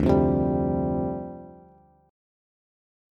Dm11 Chord
Listen to Dm11 strummed